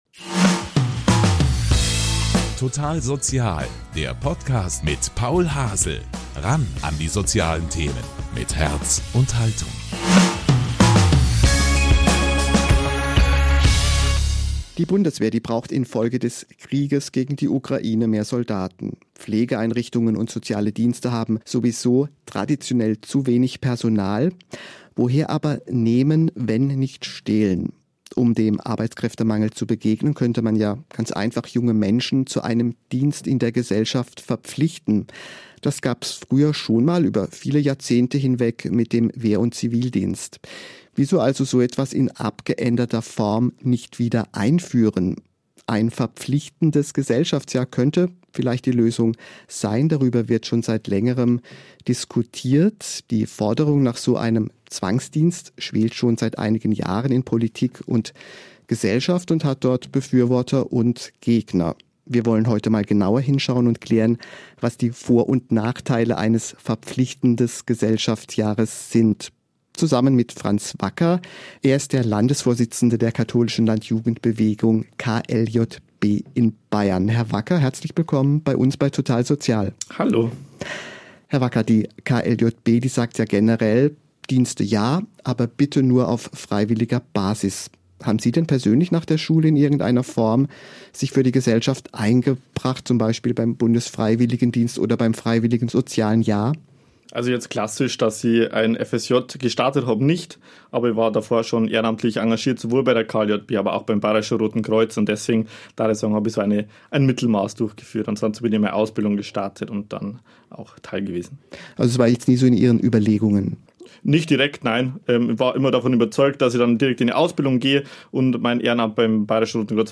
Außerdem spricht "Total Sozial" mit einer FSJlerin über ihre Erfahrungen beim Sanitätsdienst der Malteser.